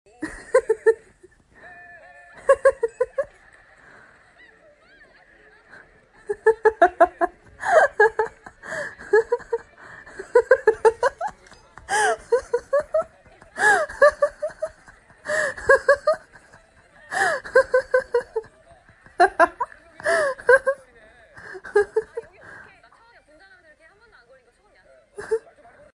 Download Chinese Funny sound effect for free.